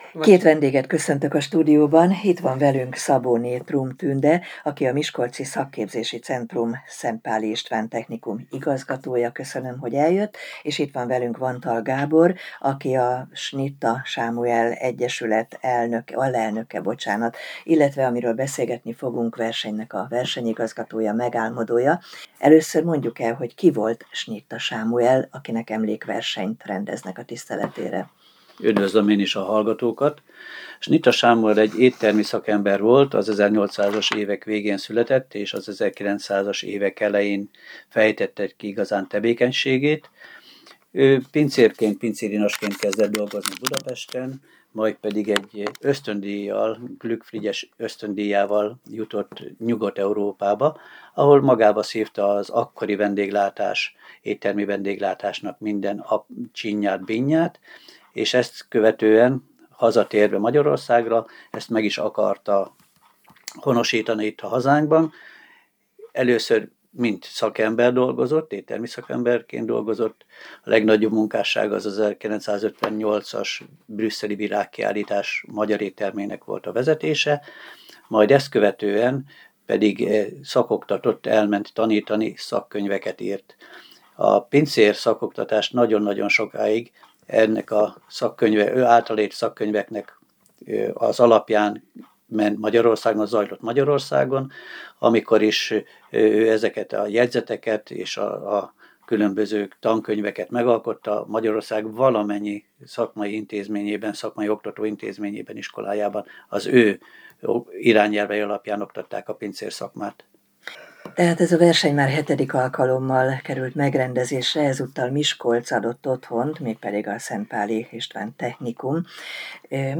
A versenynek Miskolc adott otthont. Hogy hol,és kik a győztesek, hallgassák meg a beszélgetést.